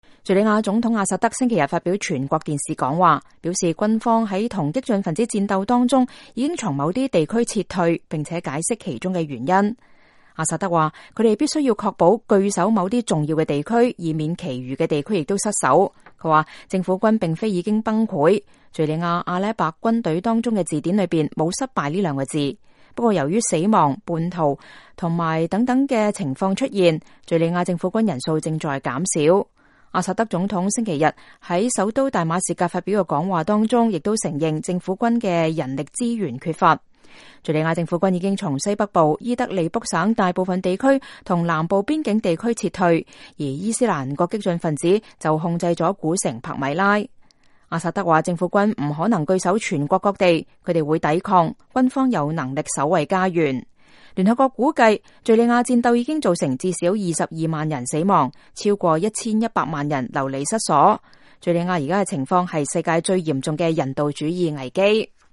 敘利亞總統阿薩德7月26日發表全國電視講話